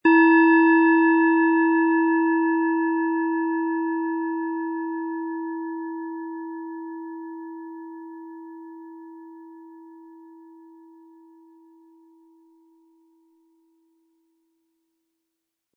Hopi Herzton
Von Hand hergestellte Klangschale mit dem Planetenton Hopi-Herzton.
Um den Original-Klang genau dieser Schale zu hören, lassen Sie bitte den hinterlegten Sound abspielen.
Spielen Sie die Hopi-Herzton mit dem beigelegten Klöppel sanft an, sie wird es Ihnen mit wohltuenden Klängen danken.
SchalenformBihar
MaterialBronze